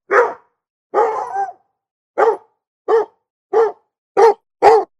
Index of /cianscape/birddataDeverinetal2025/File_origin/Noise-ESC-50/dog